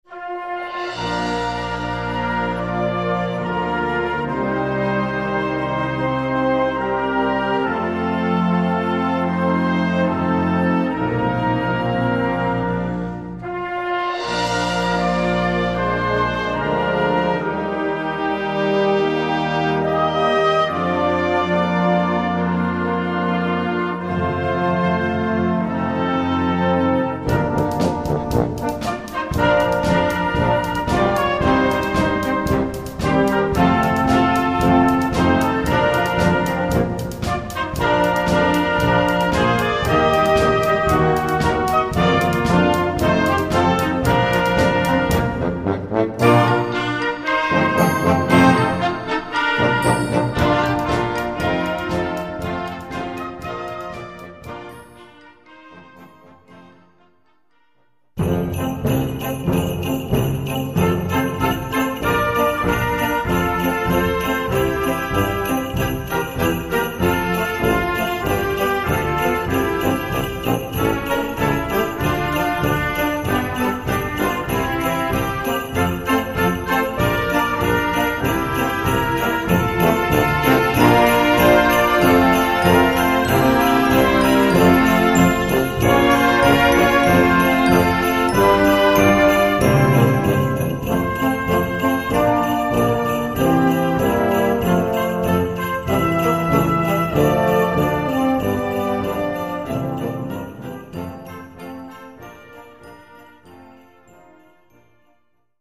Concert Band ou Harmonie ou Fanfare